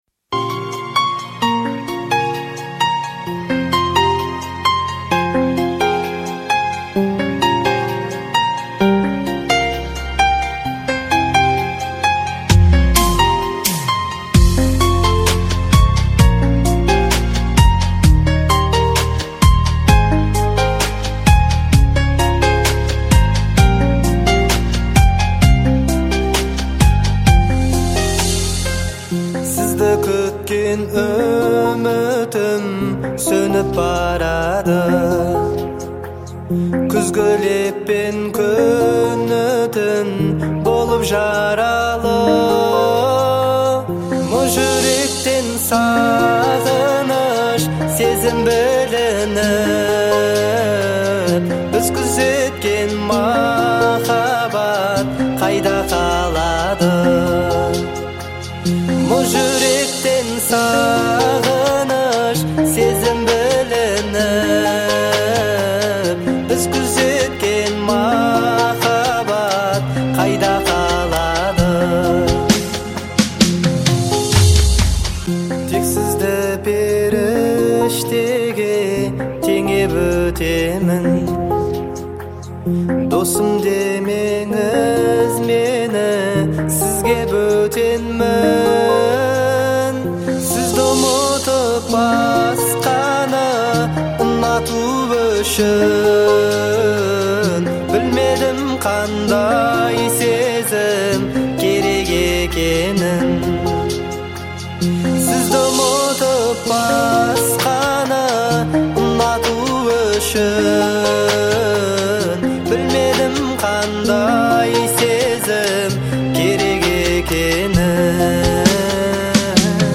трогательную балладу в жанре казахской эстрадной музыки